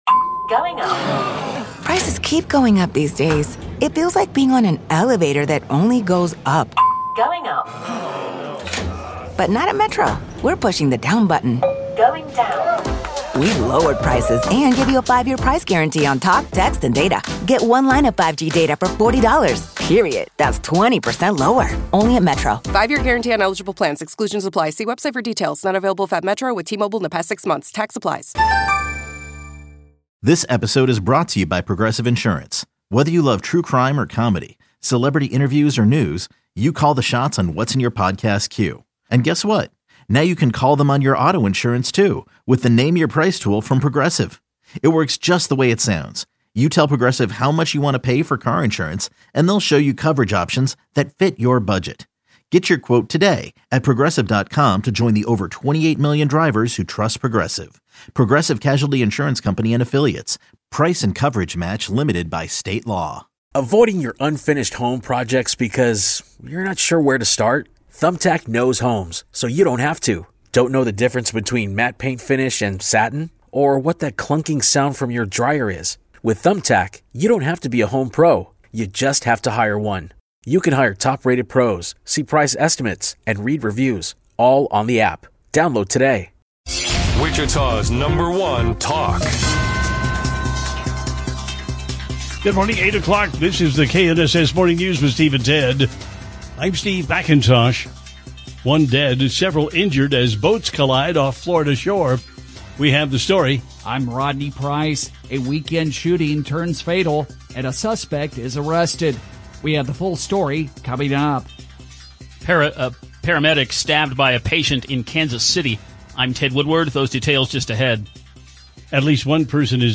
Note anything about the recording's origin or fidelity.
is a fully produced news and entertainment program aired live each weekday morning from 6a-9a on KNSS.